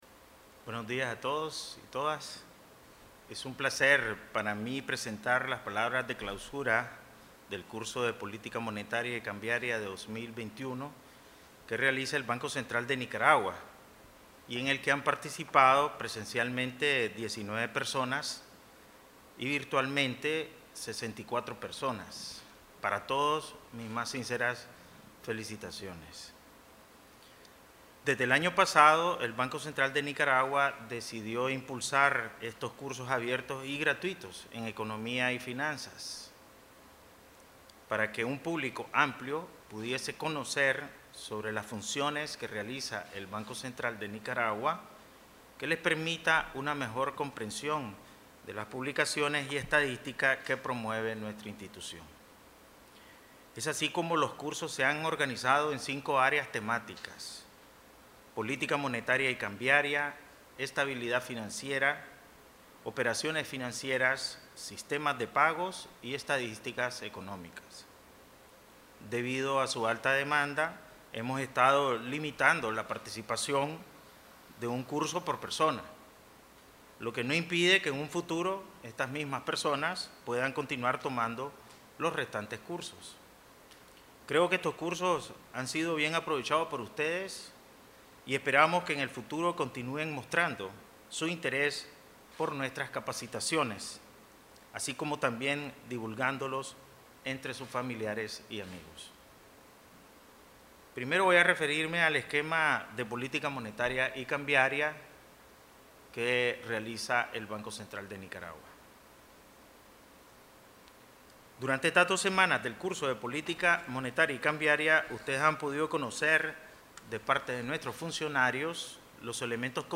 Durante la clausura, el Presidente del BCN, Ovidio Reyes R., señaló que desde el año pasado se decidió impulsar estos cursos abiertos y gratuitos en economía y finanzas, para que un público amplio pudiese conocer sobre las funciones que realiza el BCN y que permita una mejor comprensión de las publicaciones y estadísticas que provee la institución.
Palabras_AS_clausura_Curso_Economico_y_Financiero_2021.mp3